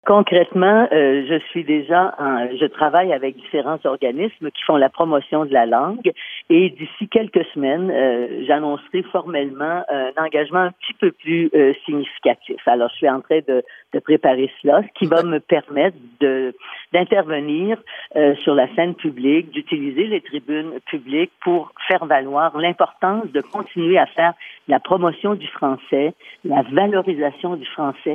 Pauline Marois fue entrevistada sobre ese tema este miércoles 4 de septiembre en el programa matutino Tout un matin (Toda una mañana) de la radio francesa de Radio Canadá y explica su preocupación.